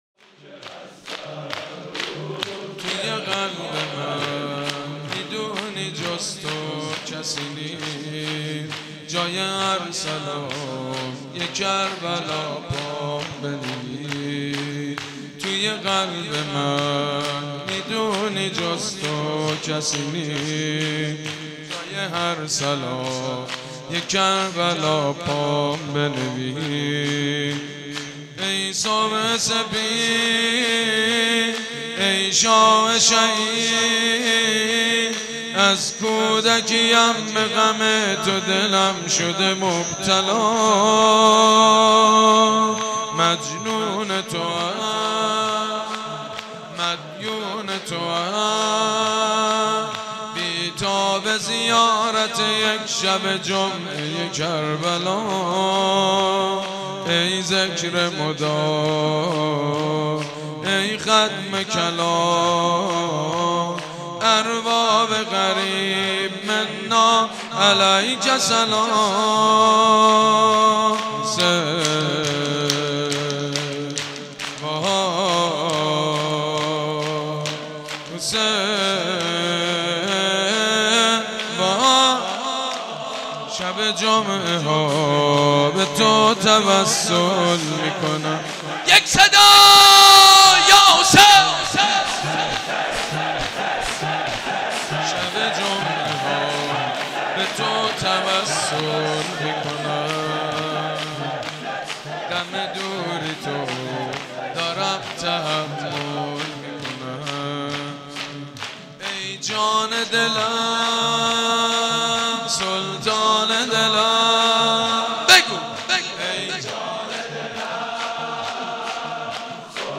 شور ٣.mp3